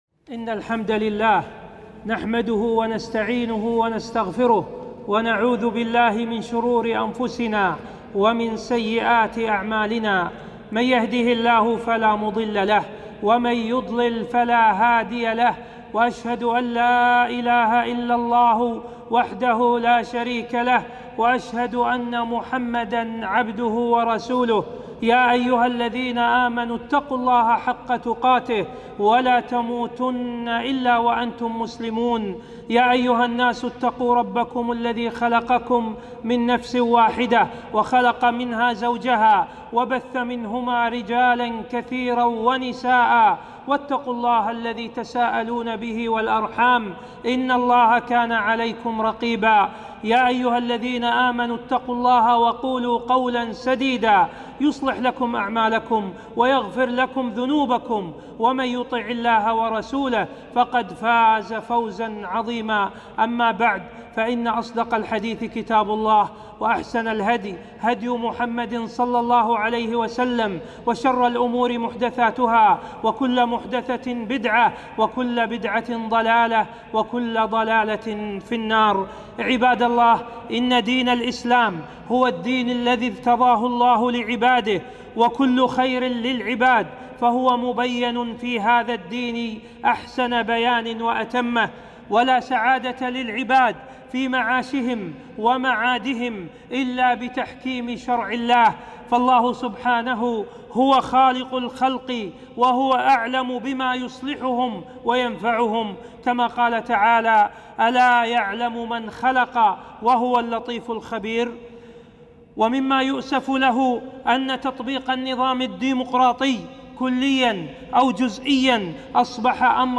خطبة - توجيهات شرعية حول الترشح للمجالس التشريعية والتصويت فيها